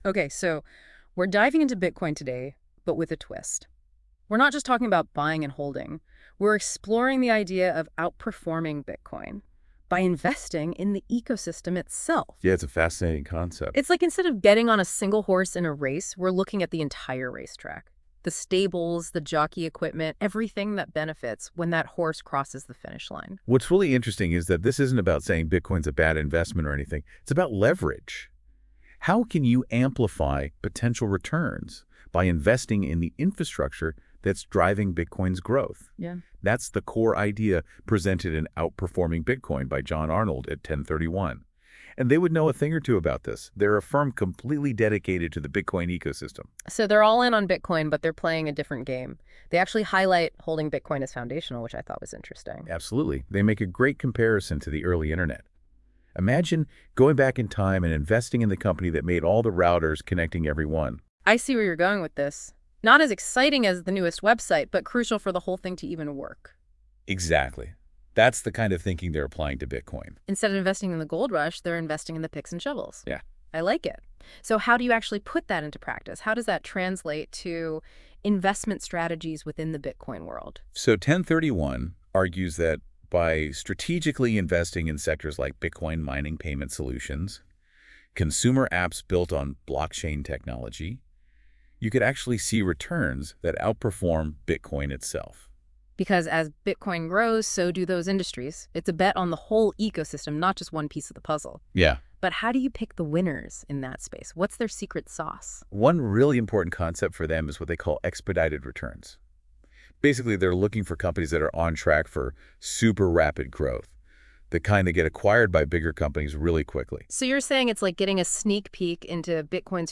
I used AI to create a podcast style explanation of an article posted by TEN31 ( npub14py…zd6m ) titled, “Outperforming Bitcoin”.